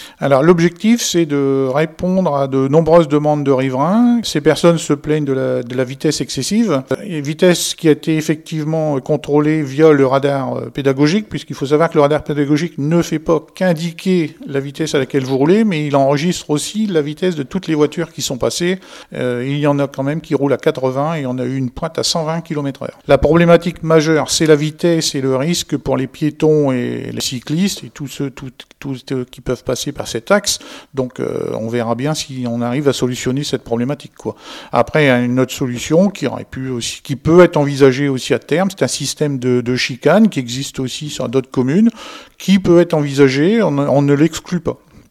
Patrick TILLIER – Maire de ST Martin lez tatinghem